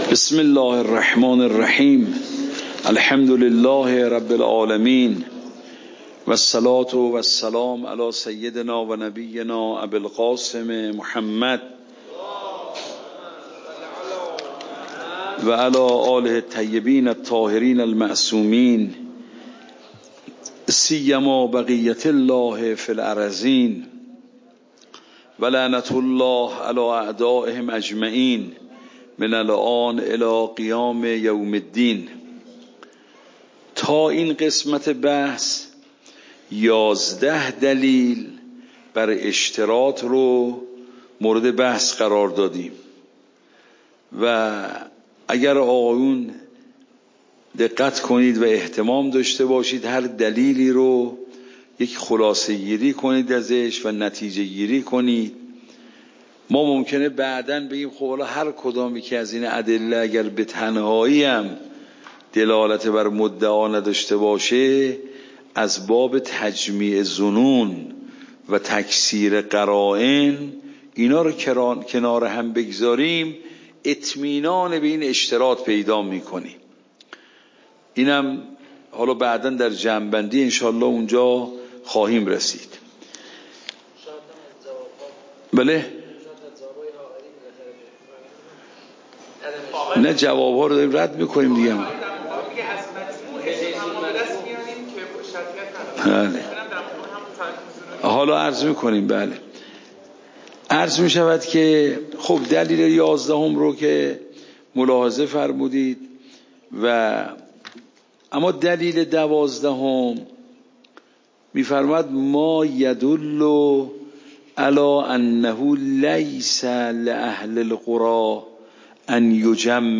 فقه خارج
صوت درس